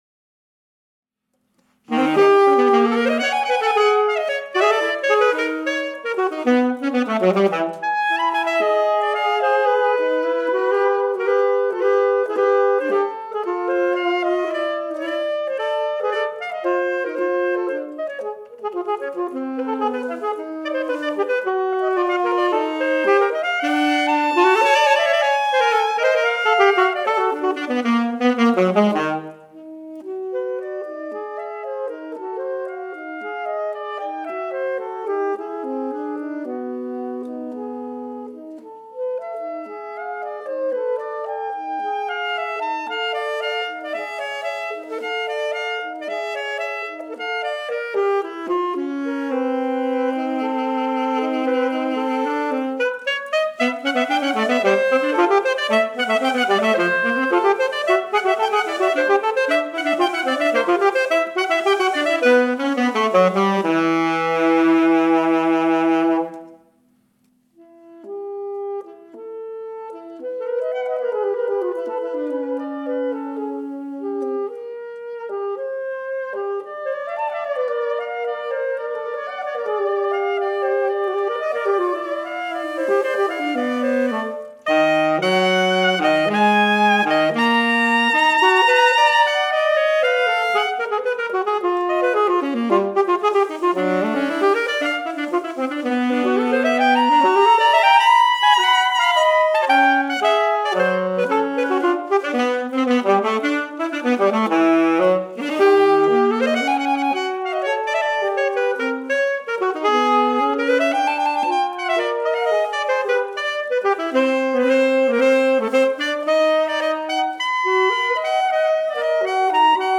My recording of the Hindemith Konzertstuck for Two Alto Saxophones. (I’m playing both parts)